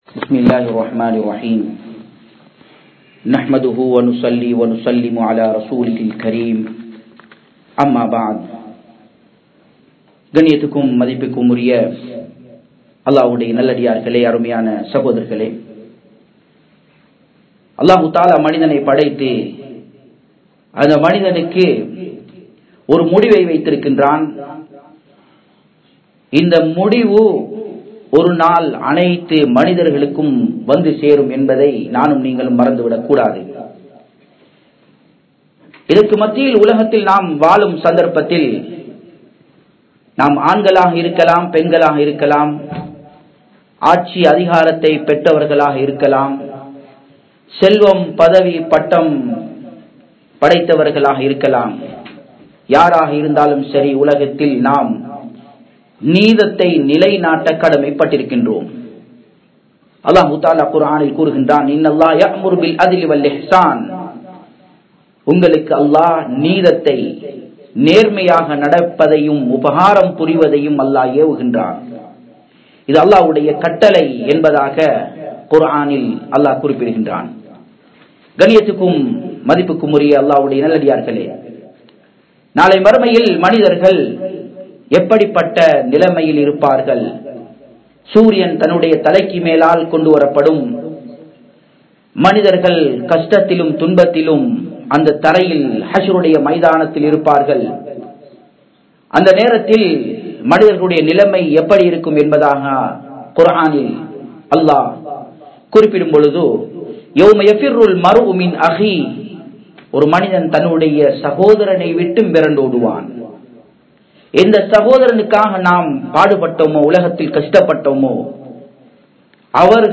Neethamaaha Nadavungal (நீதமாக நடவுங்கள்) | Audio Bayans | All Ceylon Muslim Youth Community | Addalaichenai